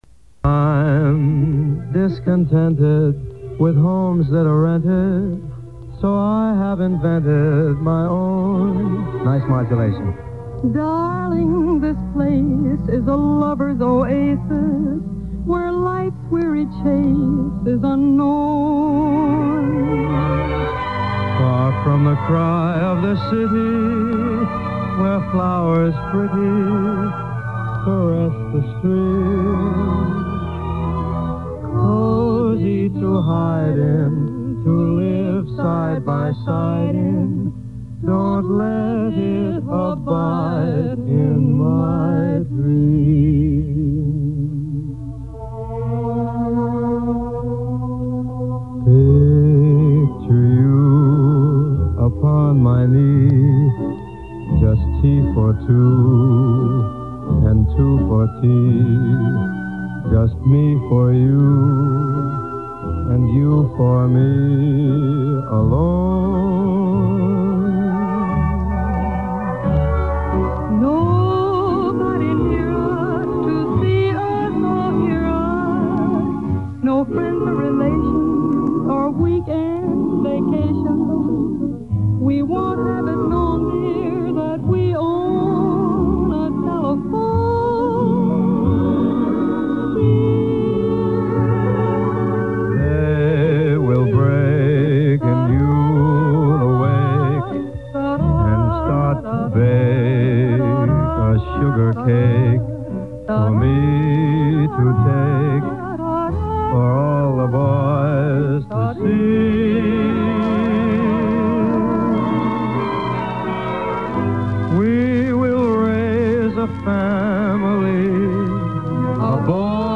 Может быть, кто-нибудь знает, с кем он поет?